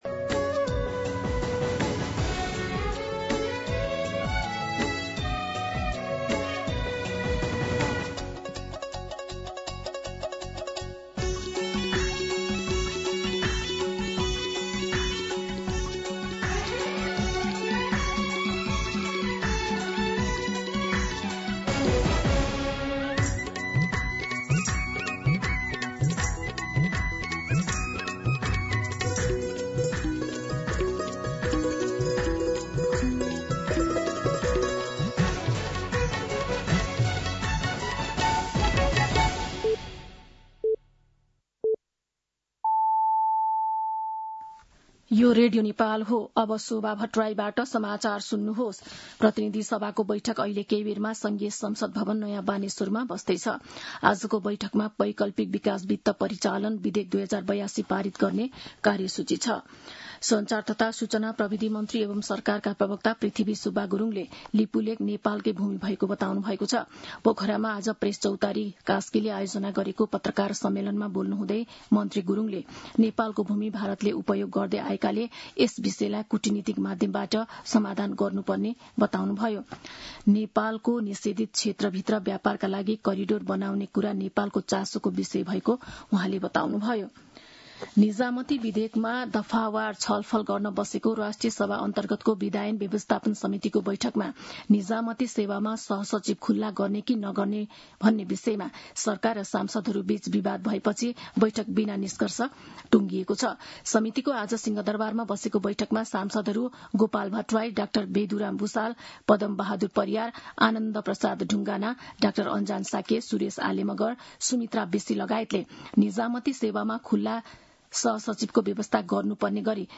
An online outlet of Nepal's national radio broadcaster
दिउँसो १ बजेको नेपाली समाचार : ६ भदौ , २०८२
1-pm-Nepali-News-1-2.mp3